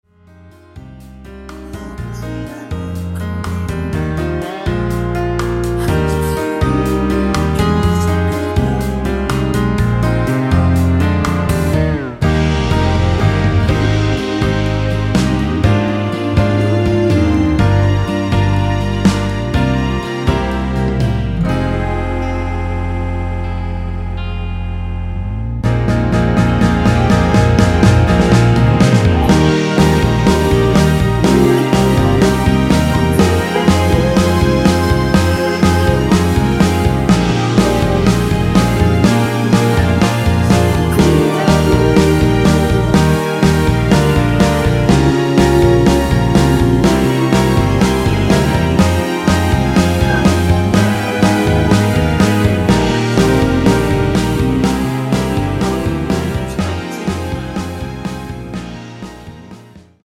원키 코러스 포함된 MR 입니다.
Eb
앞부분30초, 뒷부분30초씩 편집해서 올려 드리고 있습니다.
중간에 음이 끈어지고 다시 나오는 이유는